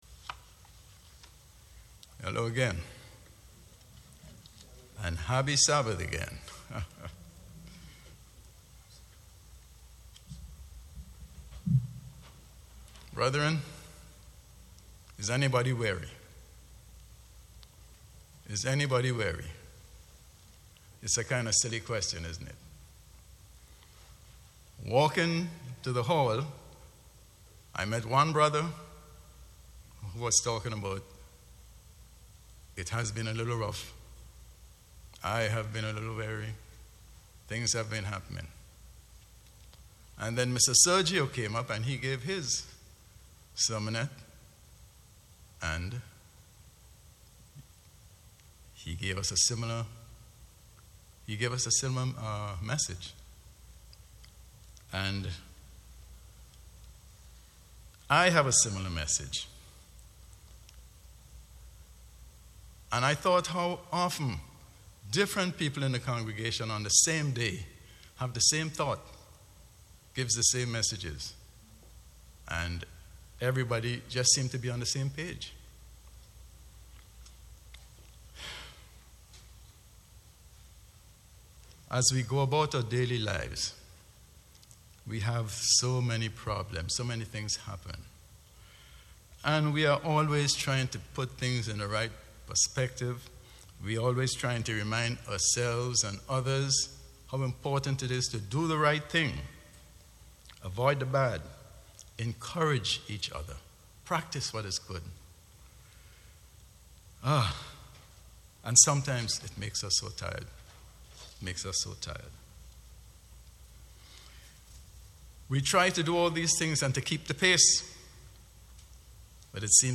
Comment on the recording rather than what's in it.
Given in New Jersey - North New York City, NY